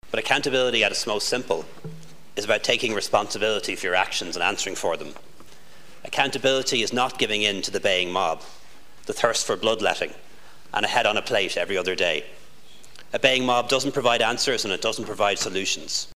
Taoiseach Leo Varadkar defended Simon Harris during the debate on the issue yesterday: